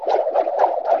sling_swing.ogg